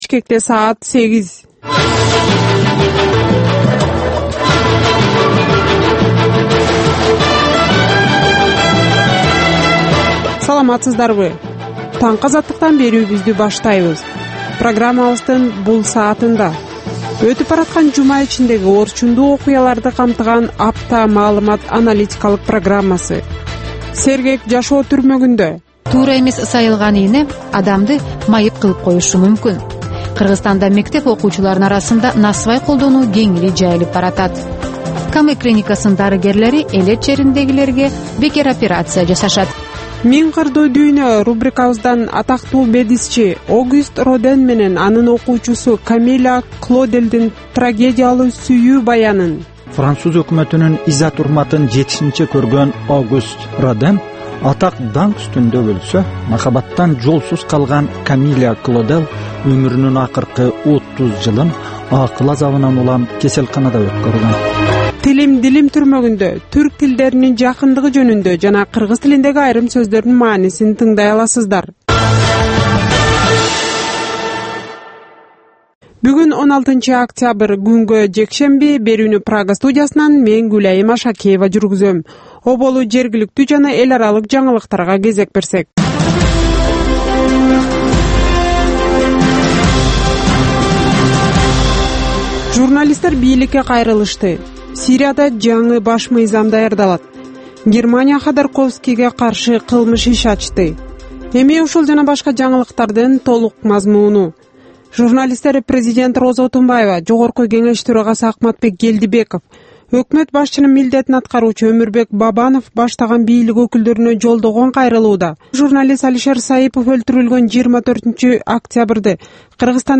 Азаттыктын кабарлары